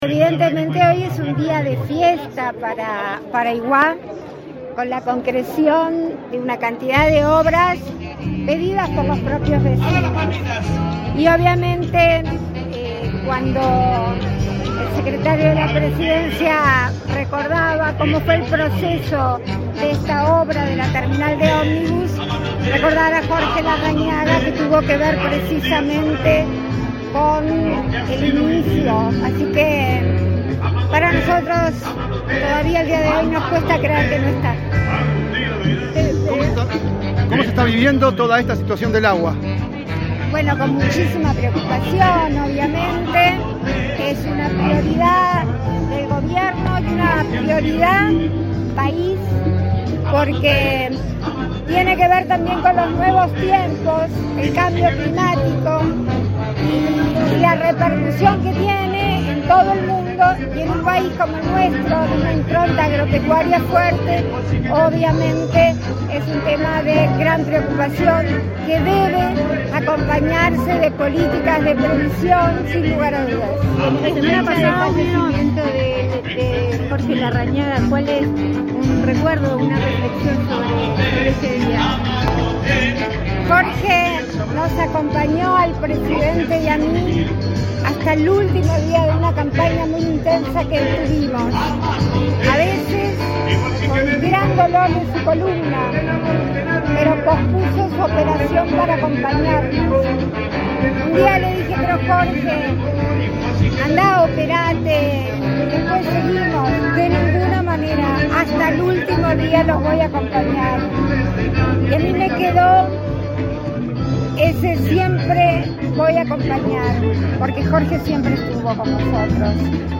Declaraciones a la prensa de la vicepresidenta de la República, Beatriz Argimón
Declaraciones a la prensa de la vicepresidenta de la República, Beatriz Argimón 22/05/2023 Compartir Facebook X Copiar enlace WhatsApp LinkedIn Este 22 de mayo, el Gobierno inauguró obras en Aiguá, en el marco del 117.° aniversario de la localidad. Tras el evento la vicepresidenta de la República, Beatriz Argimón, realizó declaraciones a la prensa.